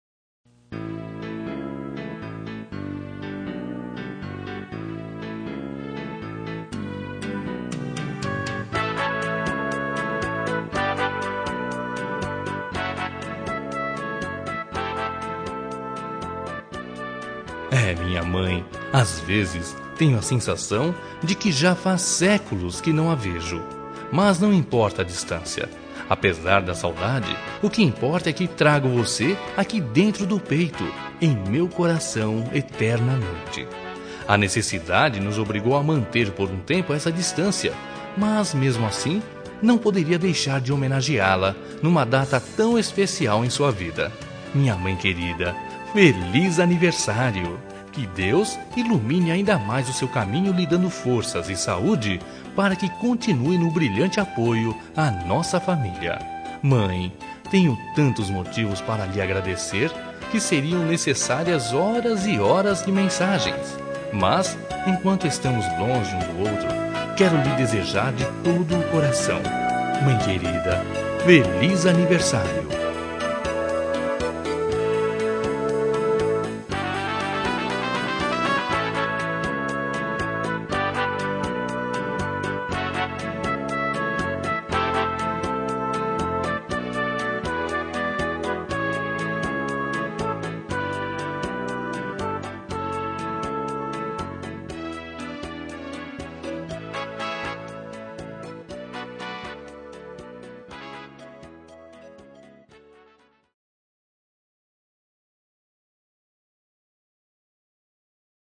Telemensagem de Aniversário de Mãe – Voz Masculina – Cód: 1455 – Distante